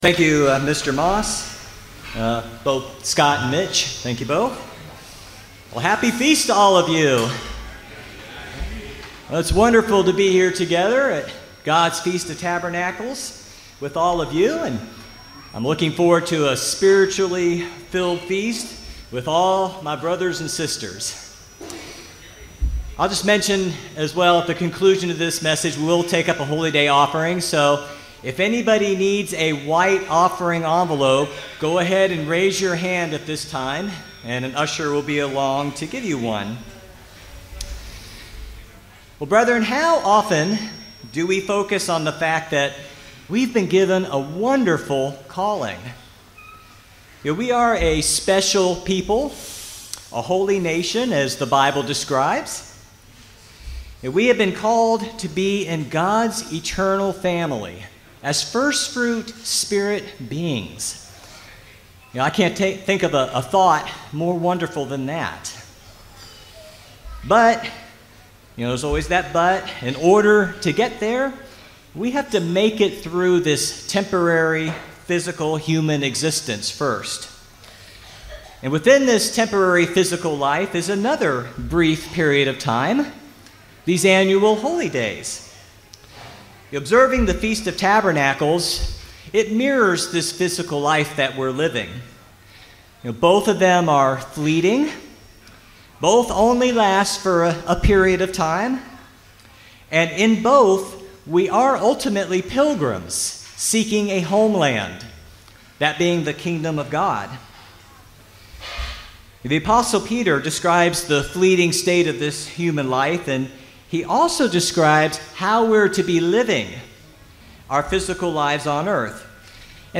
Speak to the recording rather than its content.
FoT 2024 Marina di Grosseto (Italy): 1st day